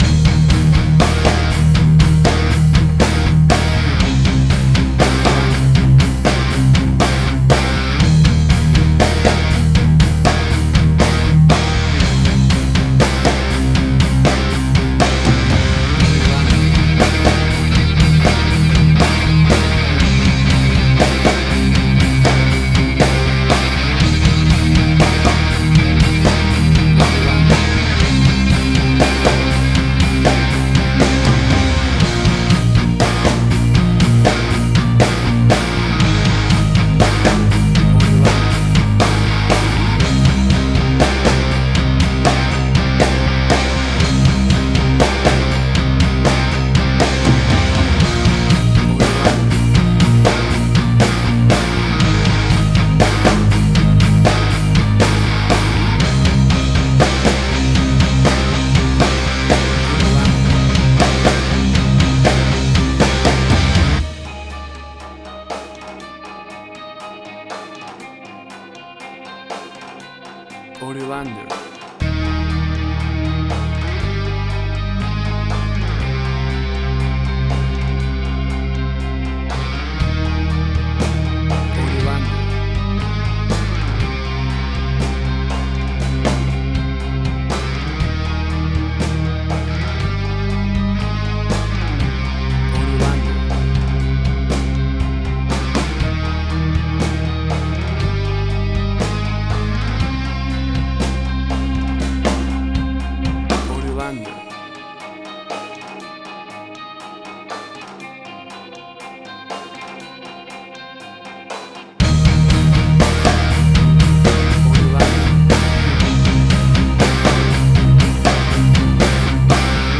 Hard Rock
Tempo (BPM): 120